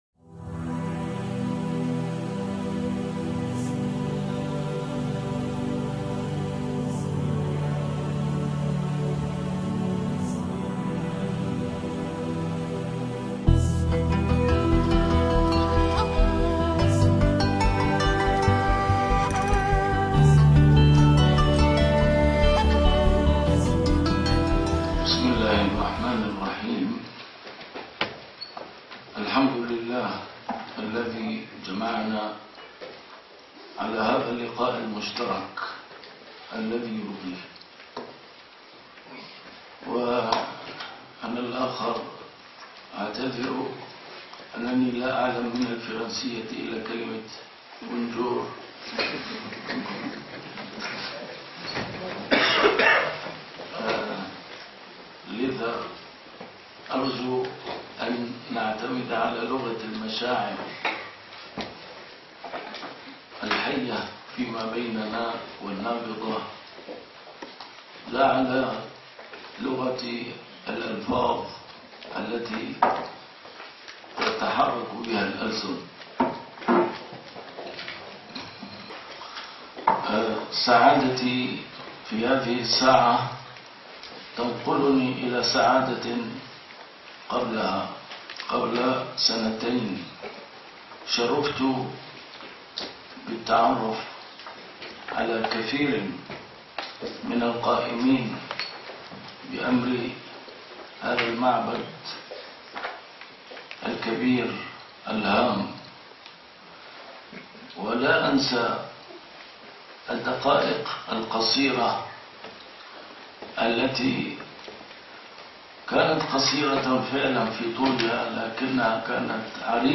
A MARTYR SCHOLAR: IMAM MUHAMMAD SAEED RAMADAN AL-BOUTI - الدروس العلمية - محاضرات متفرقة في مناسبات مختلفة - هل الإنسان مسير أم مخير؟ | محاضرة في فرنسا